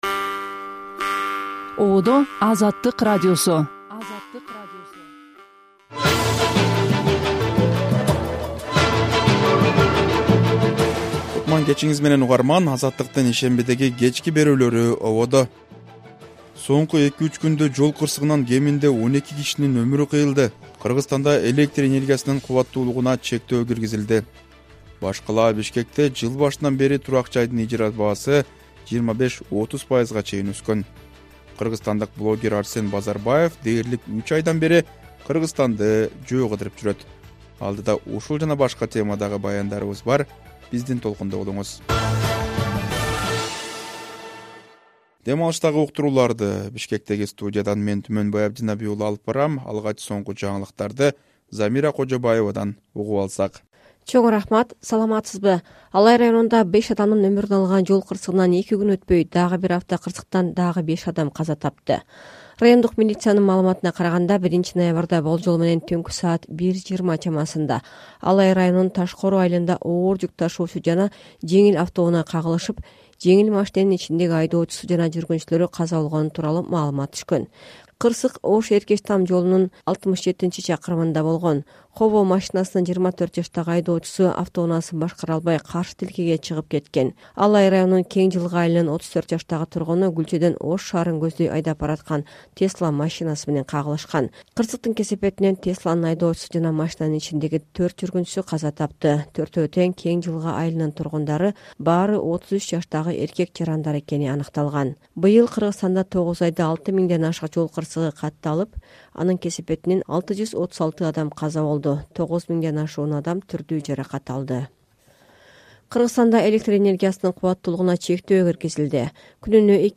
Жаңылыктар | 01.11.2025 | Кыргызстанда электр энергиясынын кубаттуулугуна чектөө киргизилди